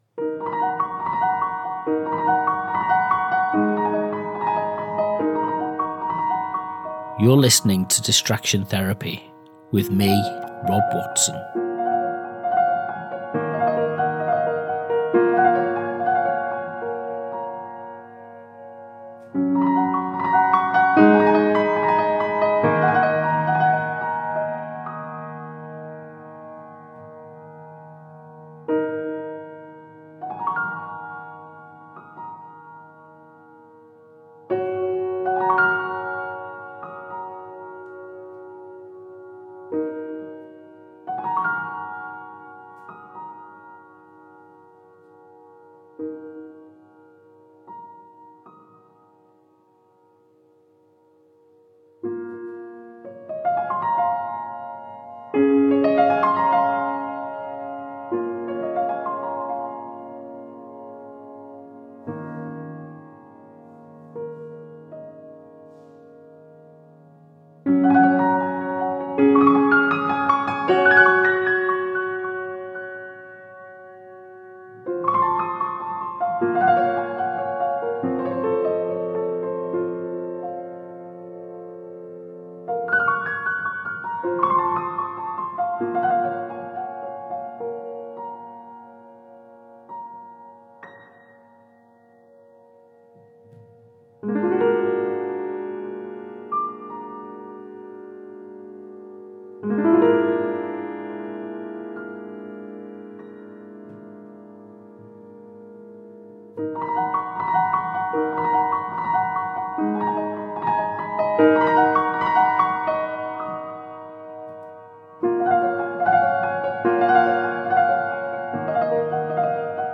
In this second part of our Summer Solstice episode, we linger in the aftermath of the turning point. The sun no longer stands still, but the memory of its pause remains—etched into the fields, the sound of the wind through grass, and the rhythms that thread through our music mix.